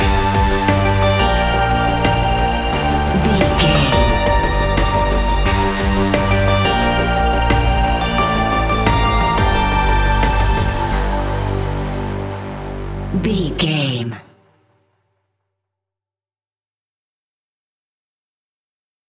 In-crescendo
Thriller
Aeolian/Minor
ominous
dark
haunting
eerie
synthesizer
drum machine
electronic music
electronic instrumentals
Horror Synths